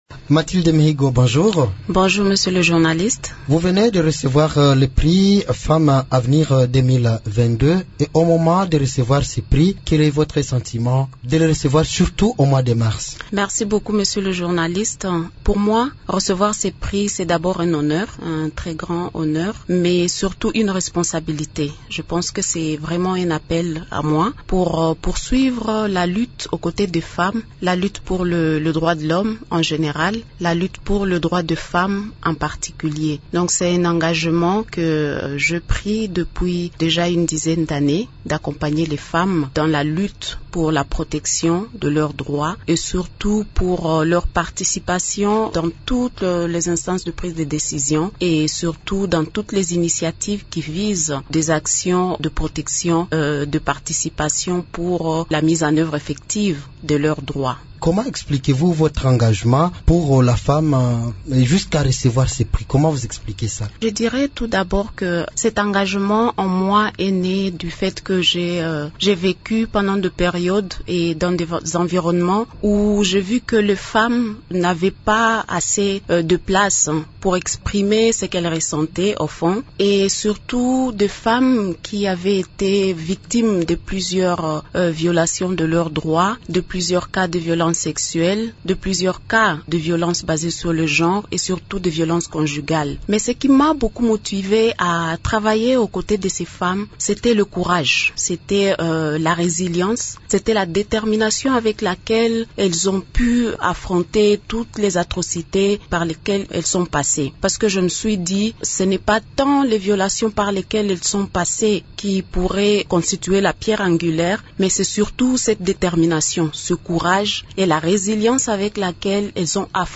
Elle s’entretient avec